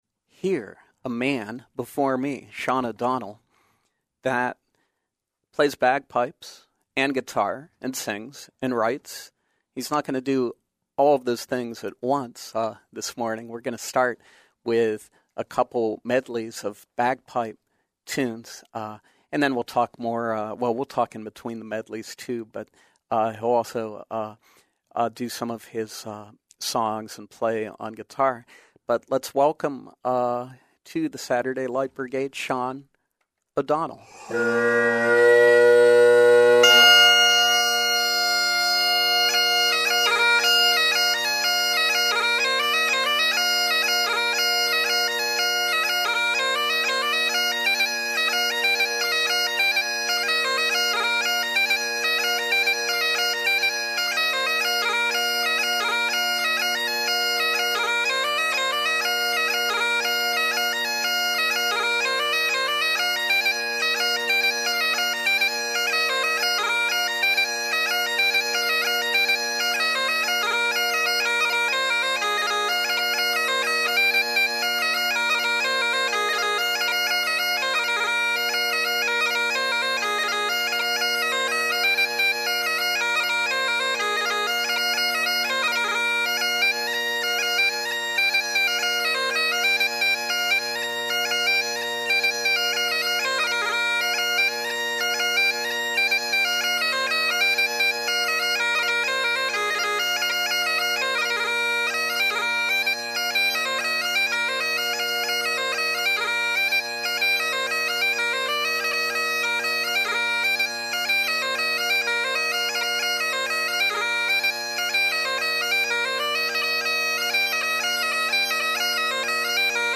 local musician
singer-songwriter and guitarist
joins us live in our studios.